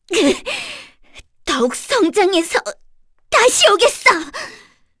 Cecilia-Vox_Dead_kr.wav